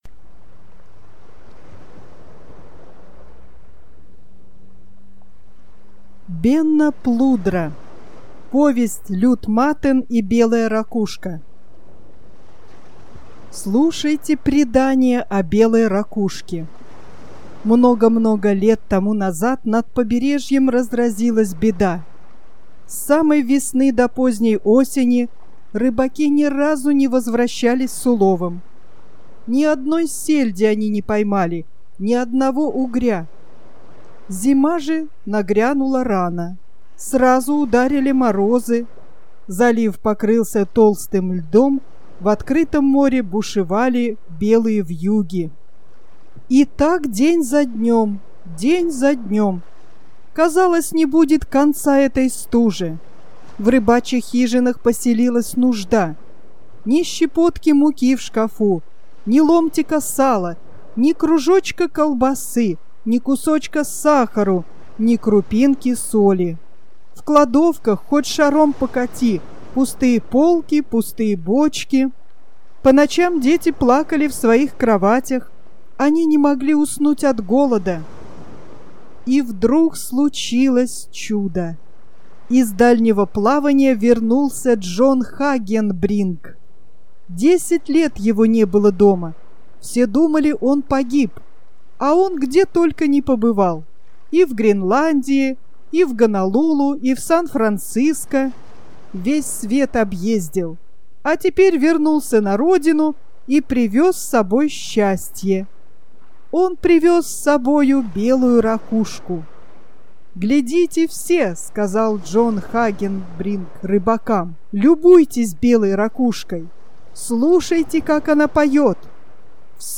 Аудио повесть немецкого писателя Бенно Плудры "Лют Матен и Белай ракушка", 1964 год.